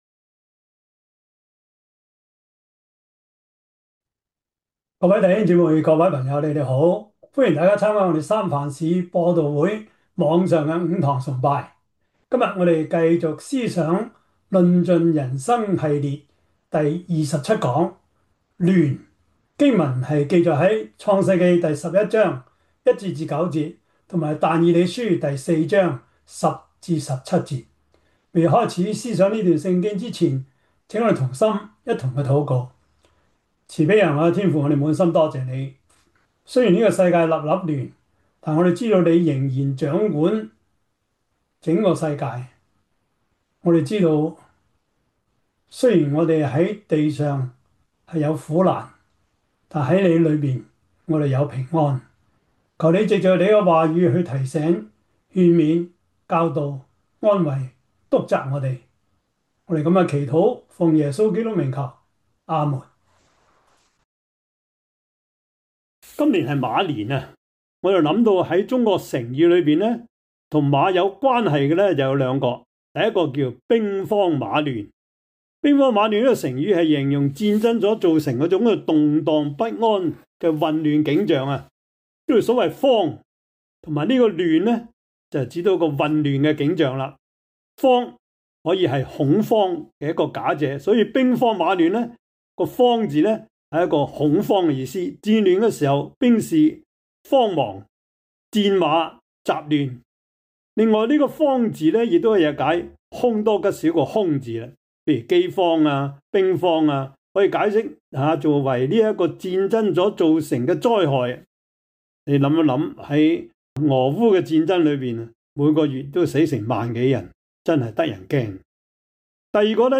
但以理書 4:10-17 Service Type: 主日崇拜 創世記 11:1-9 Chinese Union Version